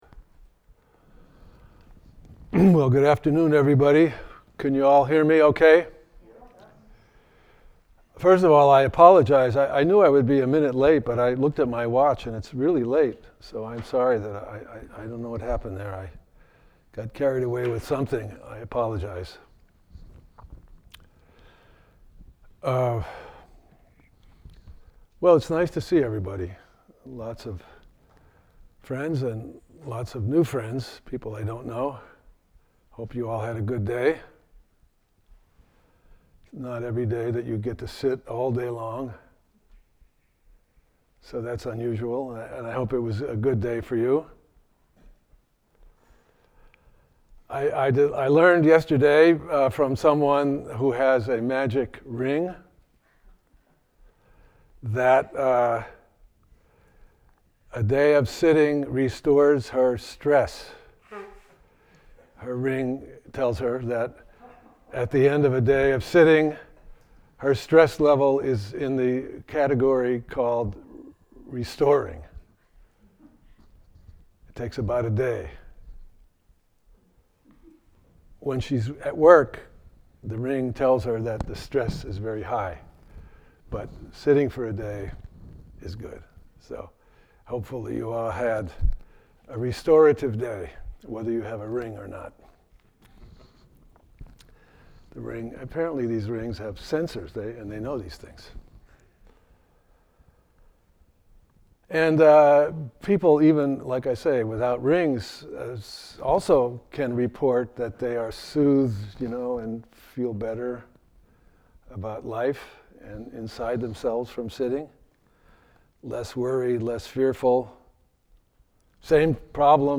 dharma talk
to the November 2025 All Day Sitting at Green Gulch Farms.